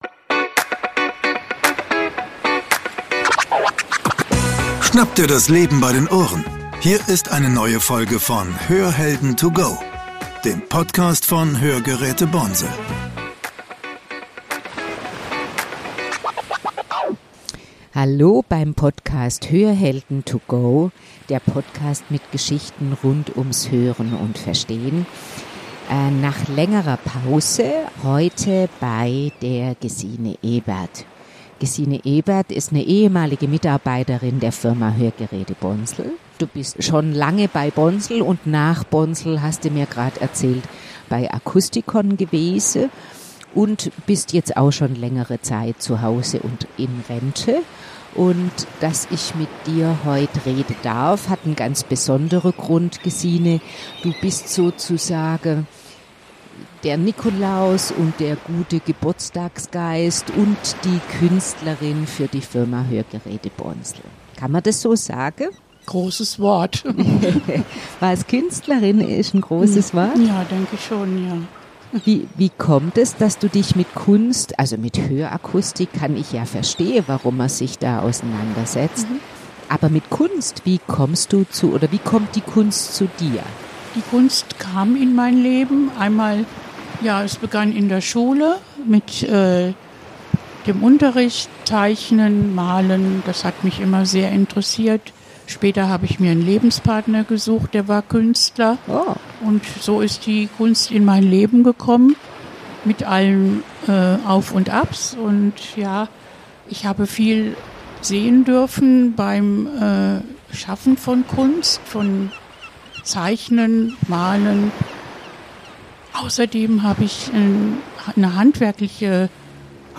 Beschreibung vor 1 Jahr Beim Zuhören bitte vom Rauschen weg und zur Sprache hin hören. Mit der Hörtrainingsvariante trainieren Sie Ihr Verstehen unter zusätzlichen Herausforderungen. Hören Sie zur Sprache hin und von den eingefügten Geräuschen weg. während des Anpassprozesses unterschiedliche Techniken Ihrer Hörsysteme vergleichen.